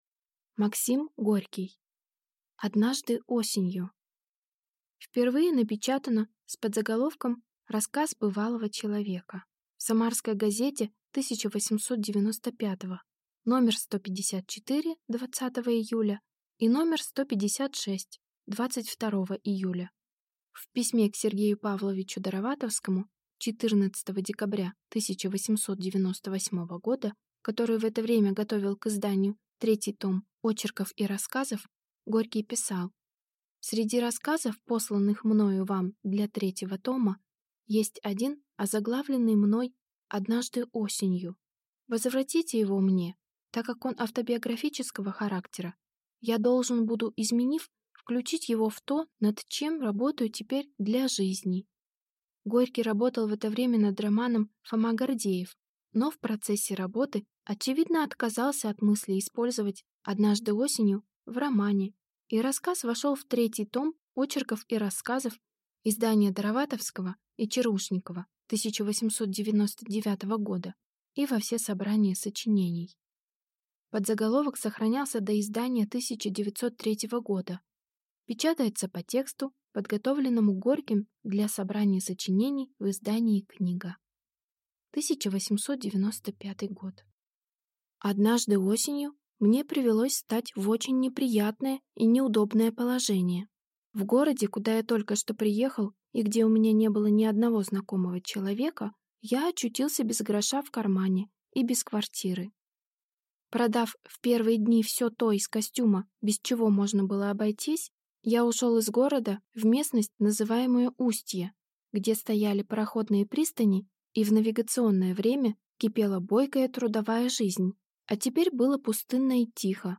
Аудиокнига Однажды осенью | Библиотека аудиокниг